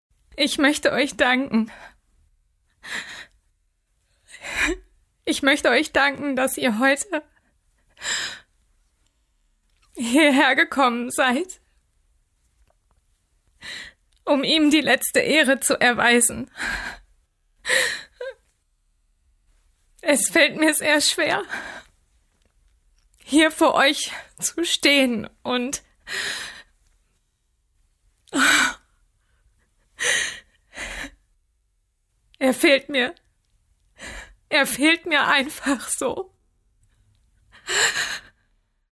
Booking Sprecherin
Lernkurs - SMITH GROUP